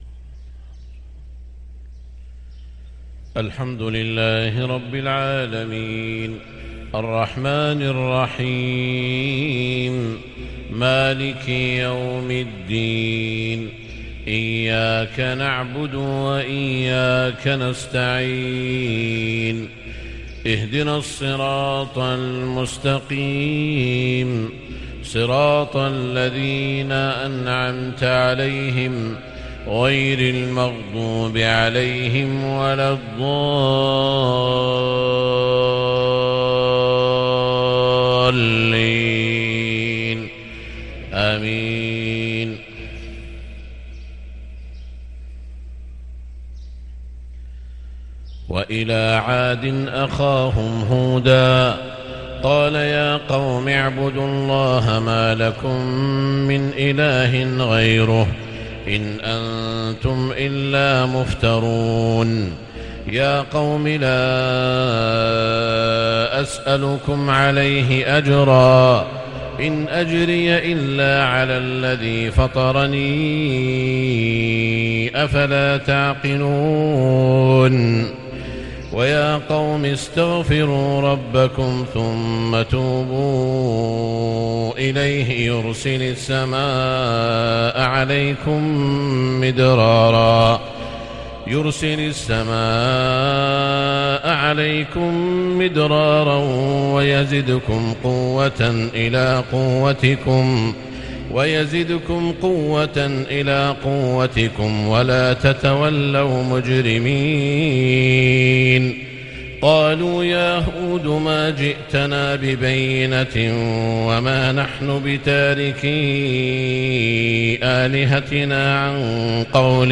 فجر الثلاثاء 4 محرم 1444هـ من هود {50 - 68} | Fajr prayer from Surah Hud 2-8-2022 > 1444 🕋 > الفروض - تلاوات الحرمين